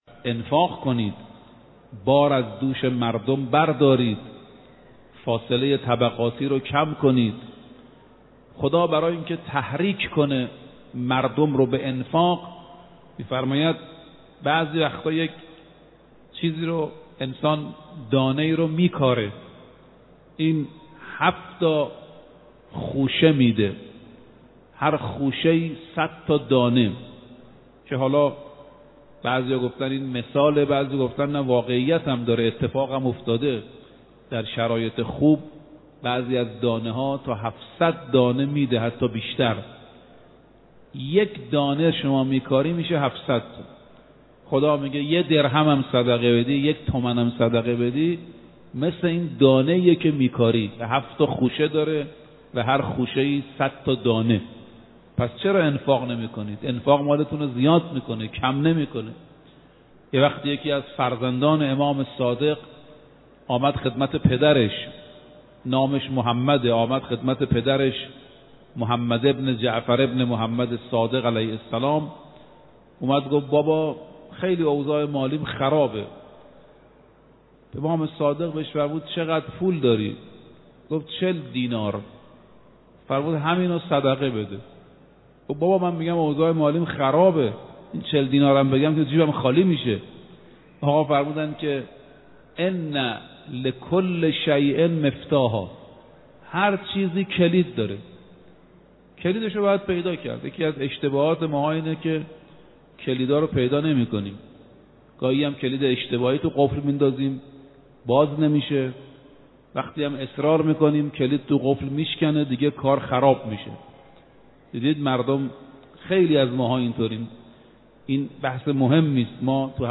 در یکی از سخنرانی‌های خود به موضوع «صدقه، کلید گشایش رزق و برکت» پرداخت که تقدیم شما فرهیختگان می شود.